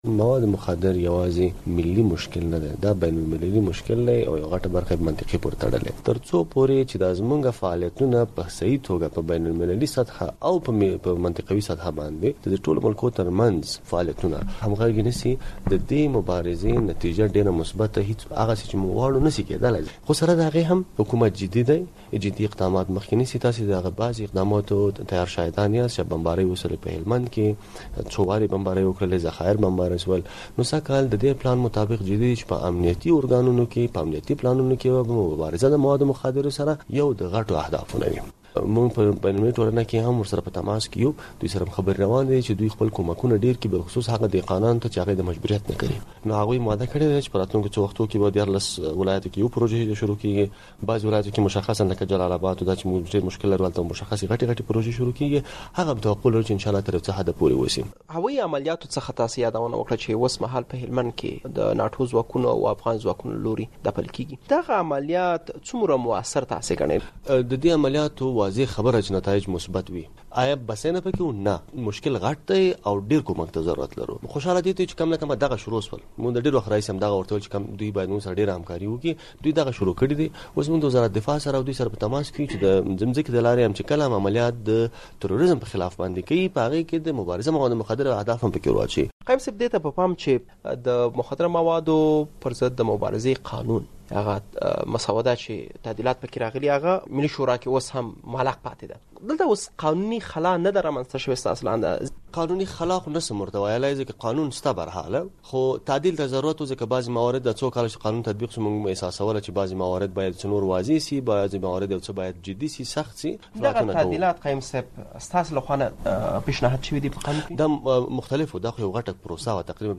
له ښاغلي قایم سره مرکه